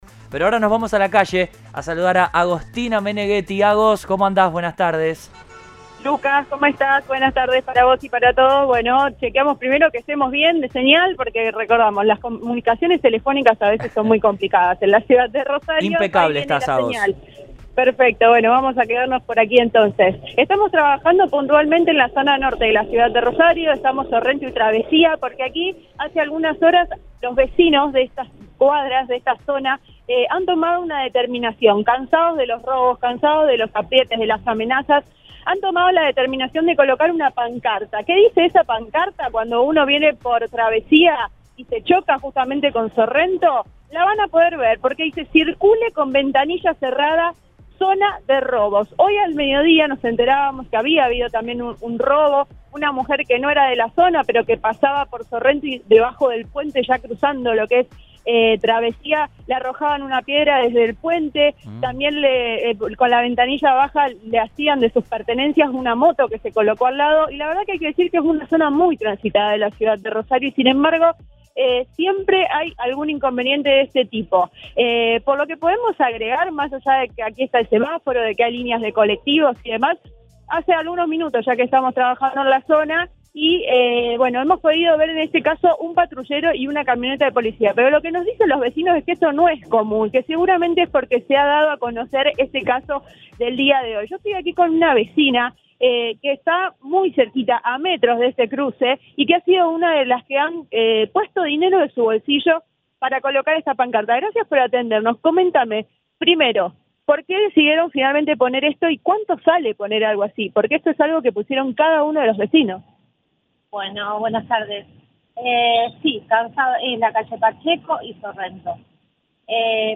Una vecina del lugar le dijo al móvil de Cadena 3 Rosario que en la modalidad “arrojan piedras del puente y hay arrebatos cuando las ventanillas se encuentran abiertas”.